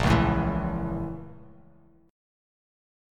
BbmM7b5 chord